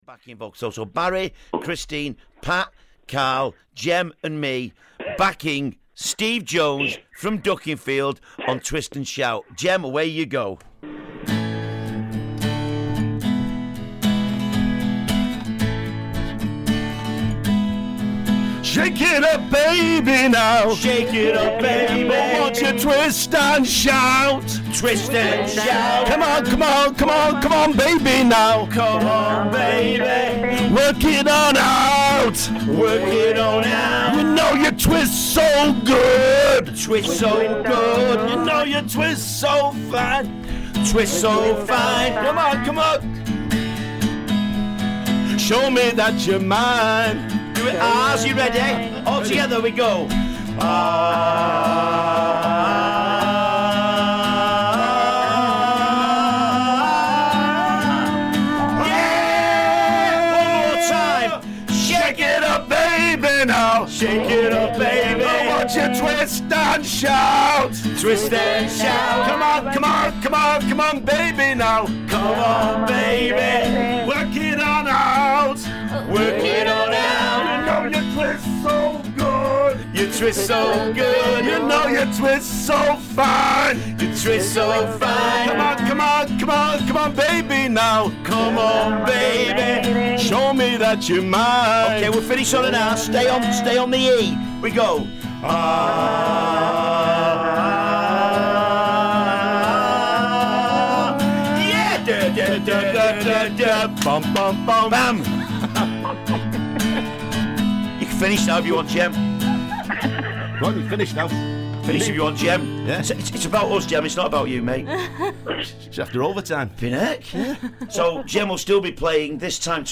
on guitar
a few special guests on backing vocals